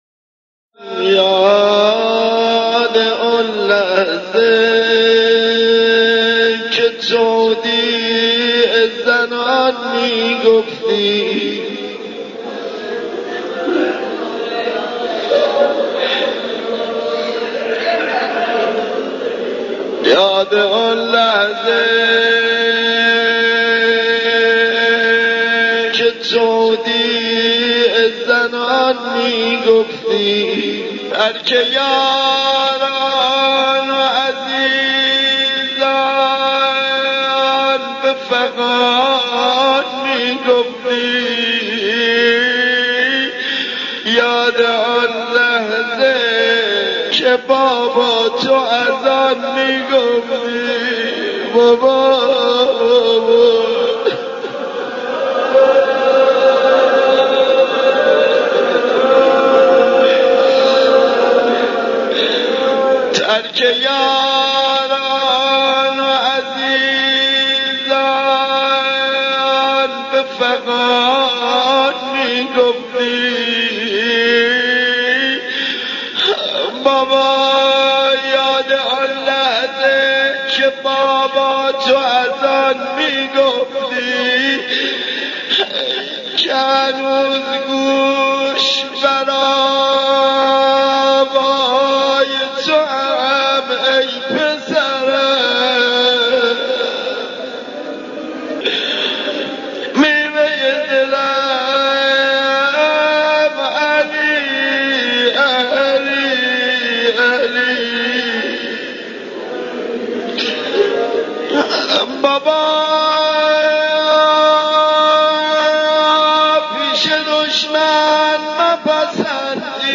مداحی قدیمی